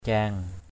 /ca:ŋ/